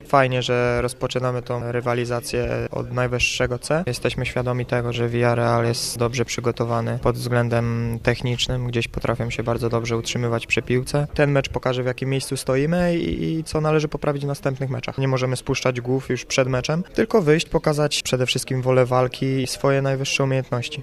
- mówi pomocnik Lecha - Michał Skóraś.